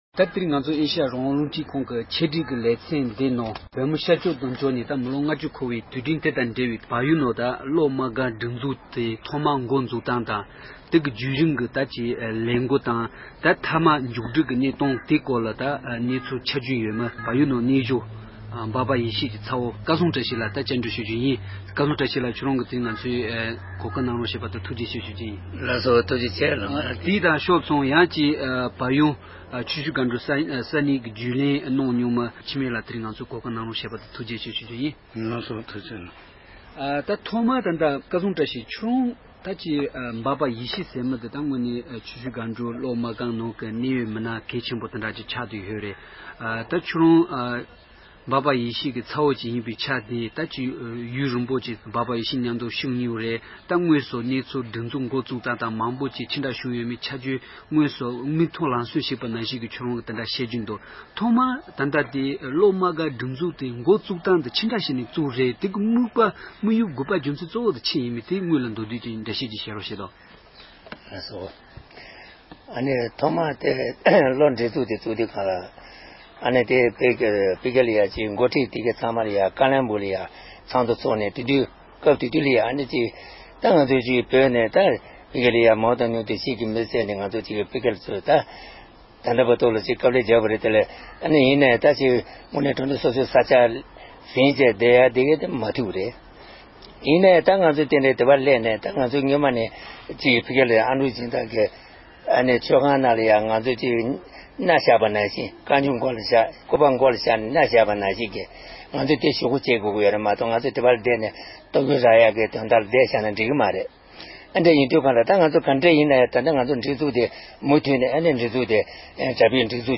བཀའ་འདྲི་ཞུས་པའི་ལེ་ཚན་དང་པོ་དེ་གསན་རོགས་དང༌༎